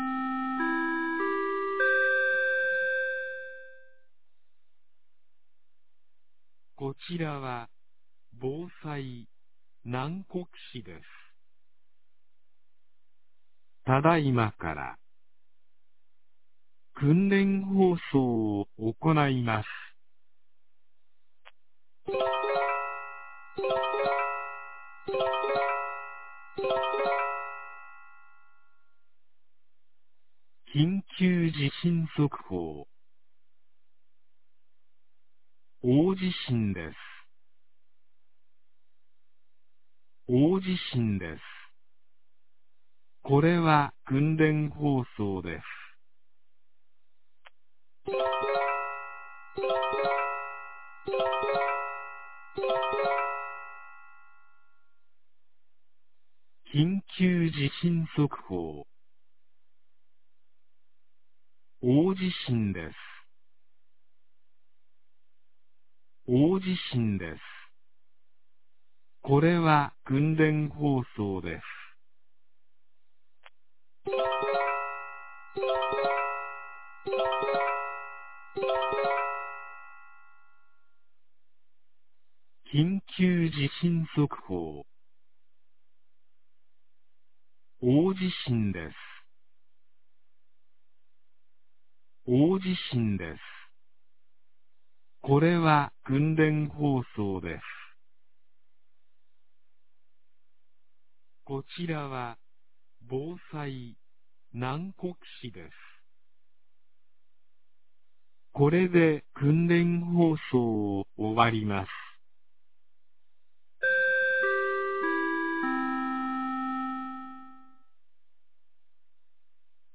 2025年11月05日 10時01分に、南国市より放送がありました。
放送音声